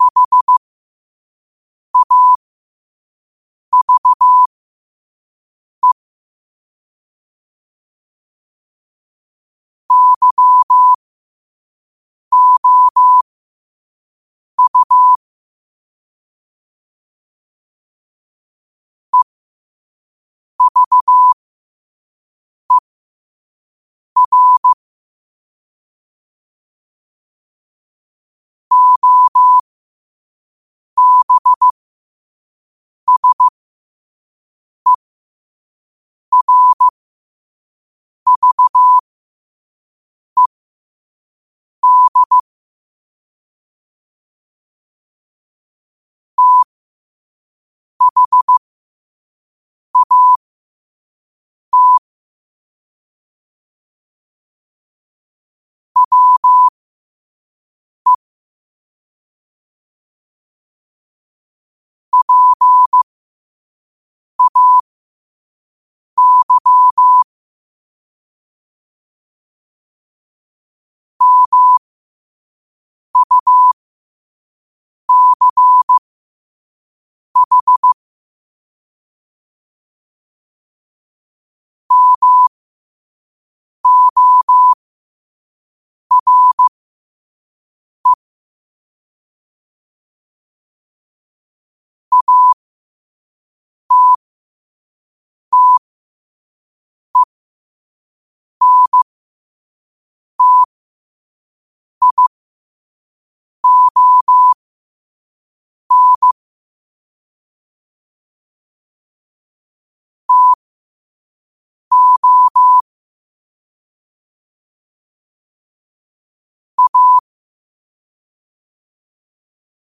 Quotes for Wed, 13 Aug 2025 in Morse Code at 5 words per minute.
Play Rate Listened List Bookmark Get this podcast via API From The Podcast New quotes every day in Morse Code. Use these to learn or practice your code copying skills.